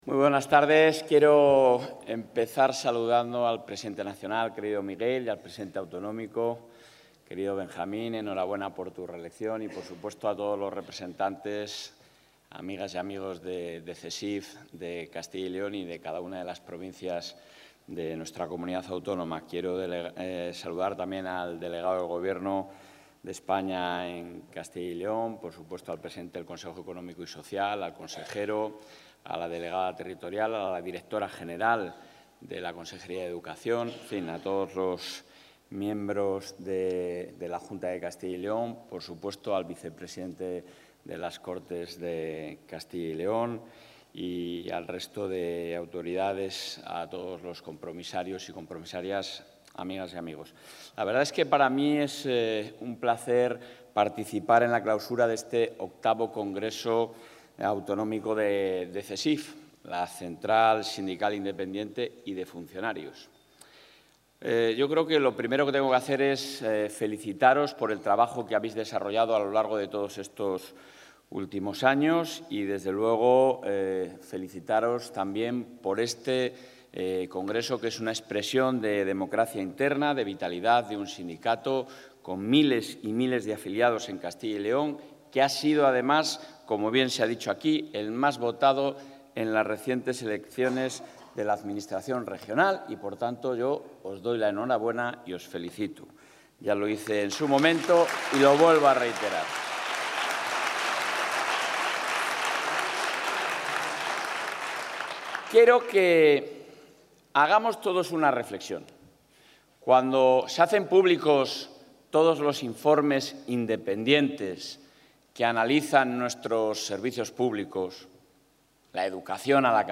Intrevención del presidente de la Junta.
El presidente de la Junta de Castilla y León, Alfonso Fernández Mañueco, ha participado en Valladolid en la clausura del VIII Congreso Autonómico de la Central Sindical Independiente y de Funcionarios (CSIF).